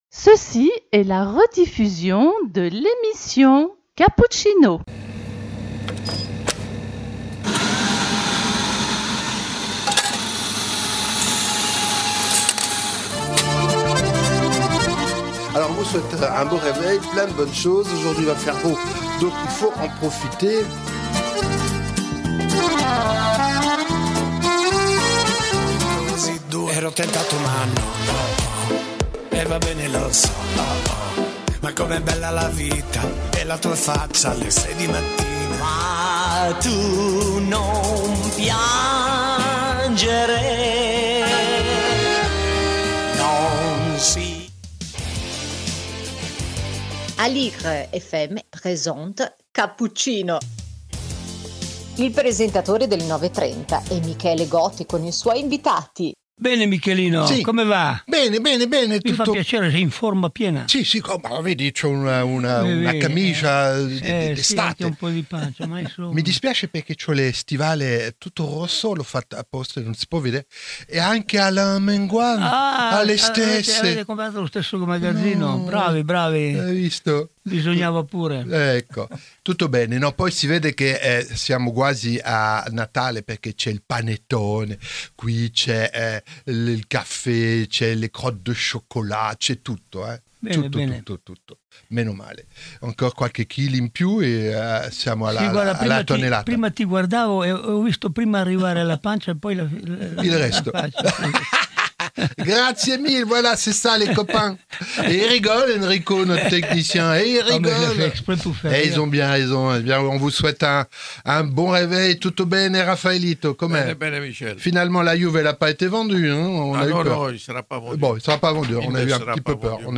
Cappuccino # 14 décembre - invité le pianiste Giovanni Mirabassi - Aligre FM